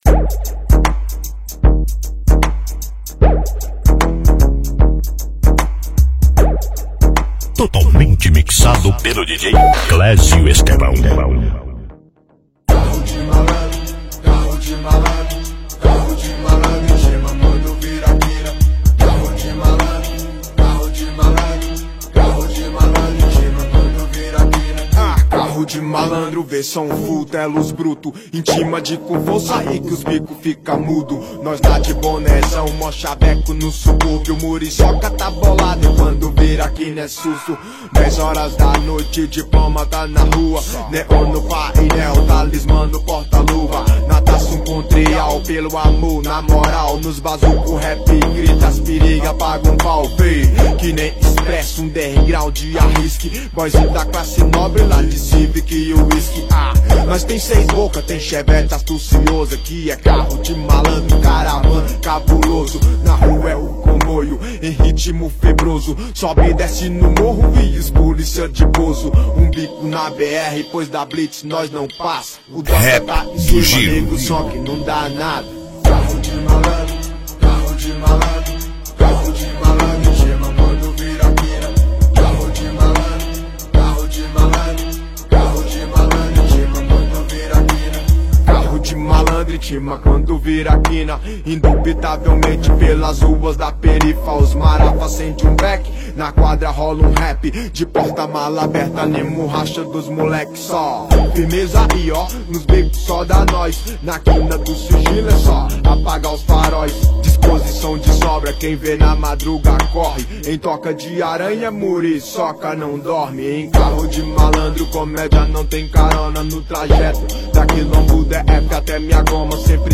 As melhores do Rap nacional